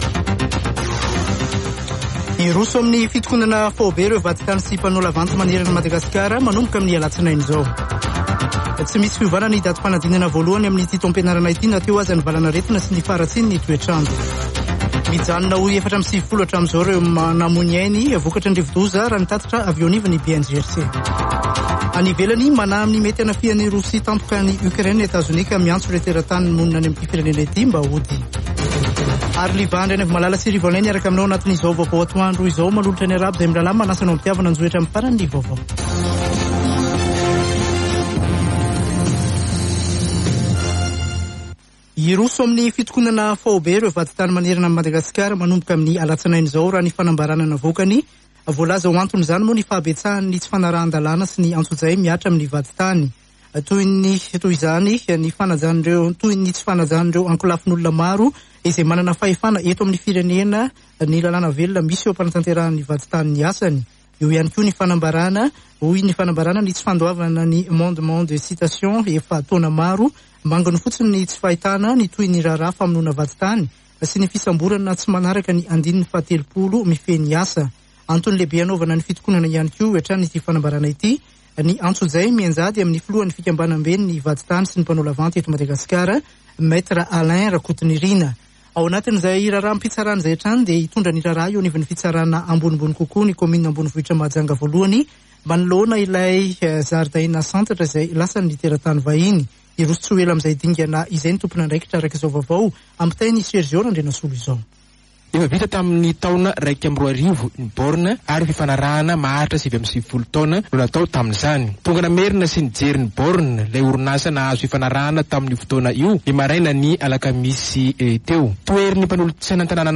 [Vaovao antoandro] Zoma 11 febroary 2022